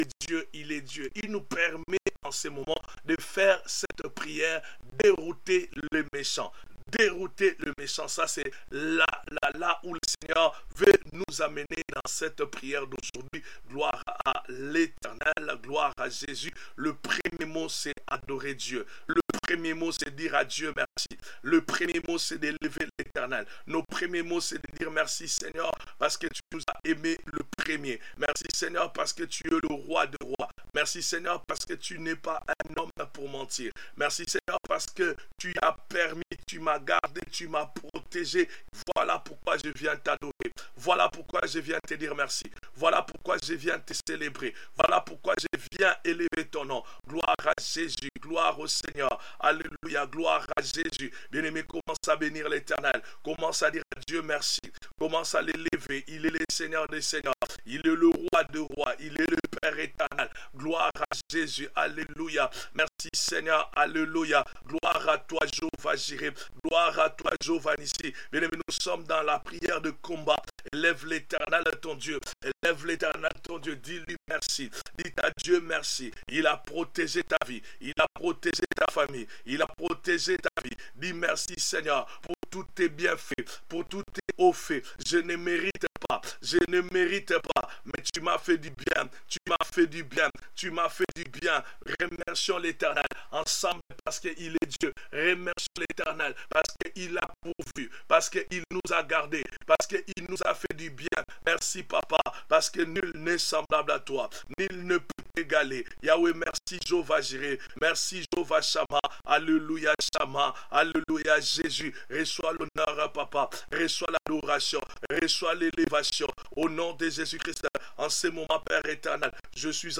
Déroutez les méchants : Une forte priere